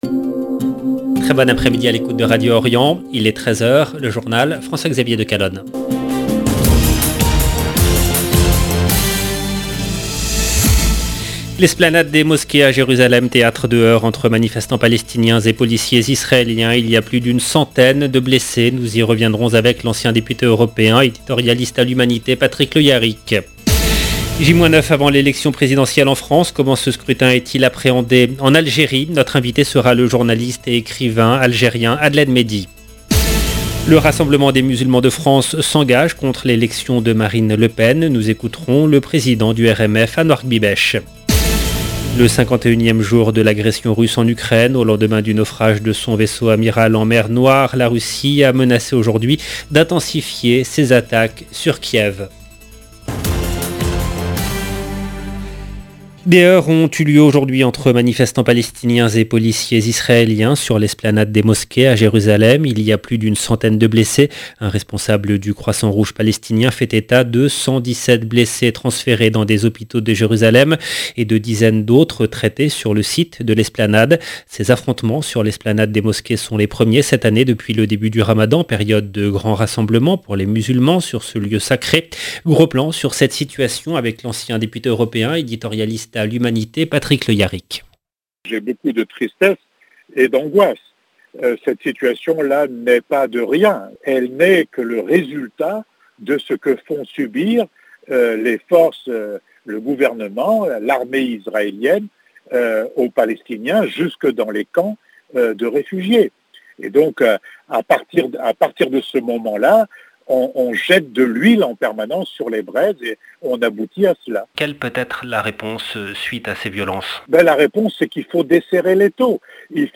LE JOURNAL DE 13 H EN LANGUE FRANCAISE DU 15/4/2022